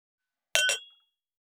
337ガラスのグラス,ウイスキー,コップ,食器,テーブル,チーン,カラン,